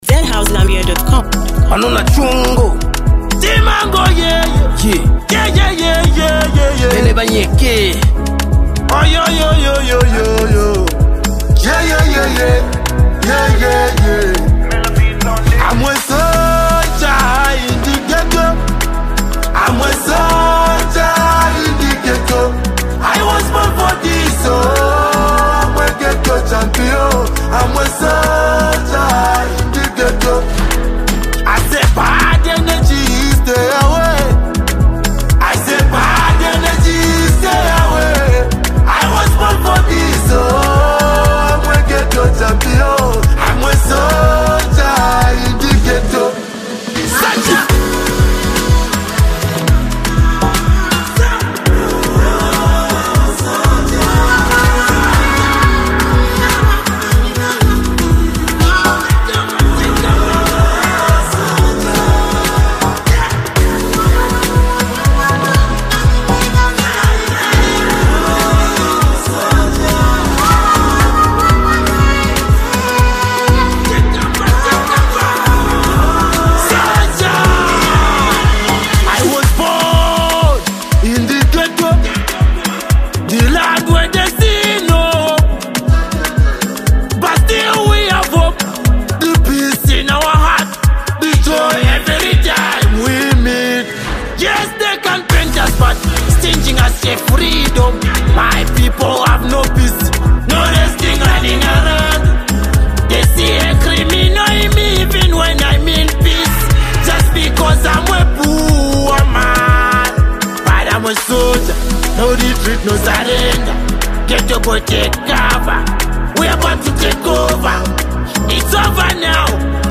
a powerful anthem of resilience and hustle.